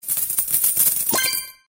coinsCollect.mp3